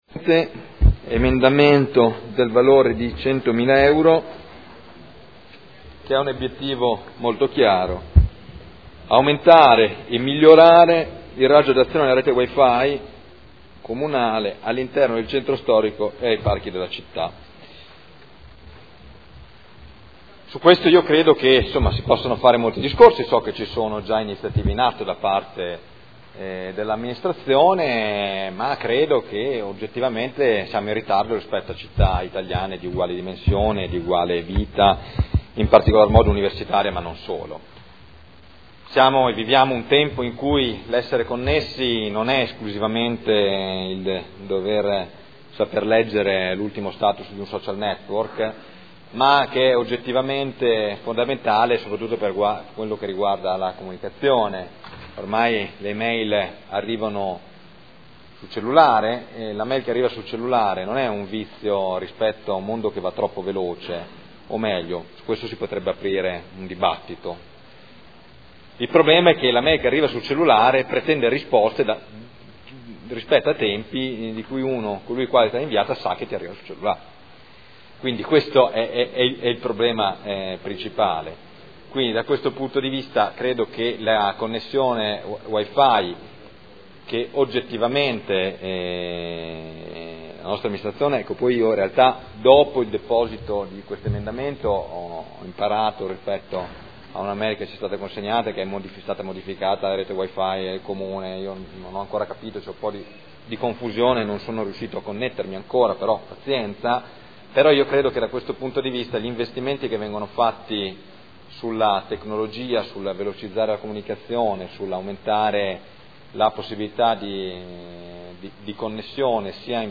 Michele Barcaiuolo — Sito Audio Consiglio Comunale